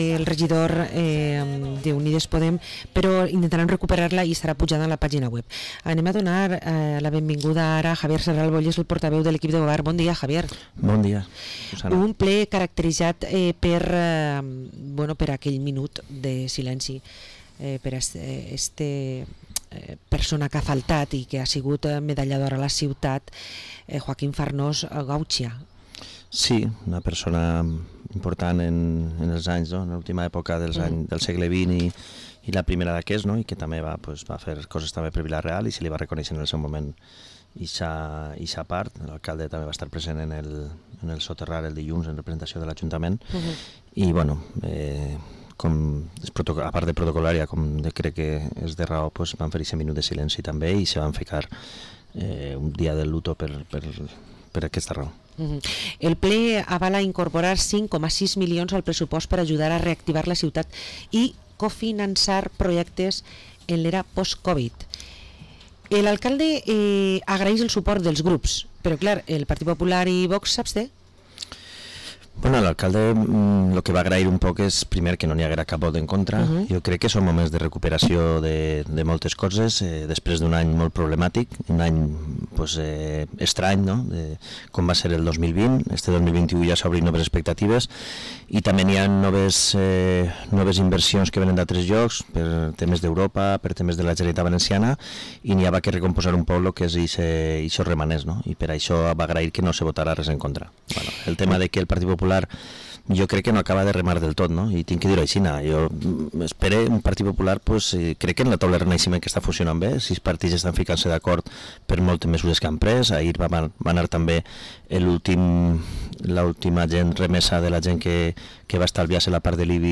Entrevista al portavoz del equipo de gobierno de Vila-real, Javier Serralvo